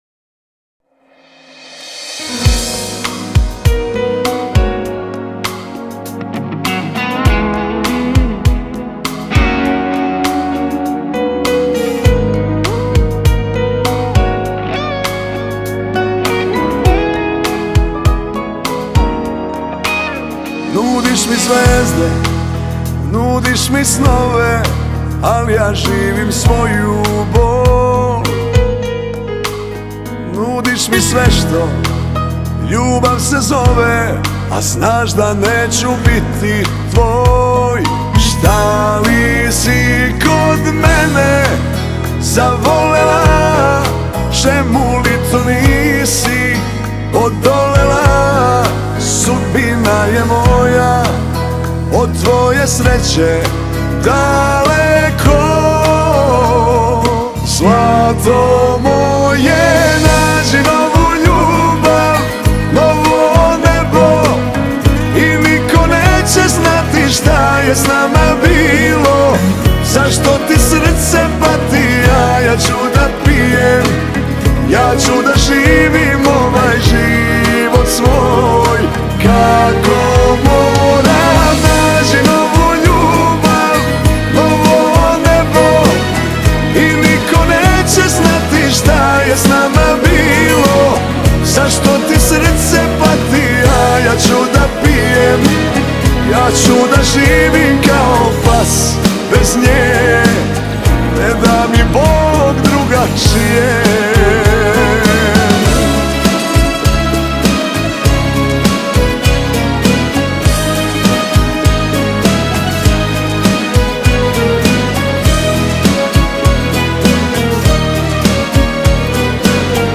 популярный сербский певец, обладатель уникального голоса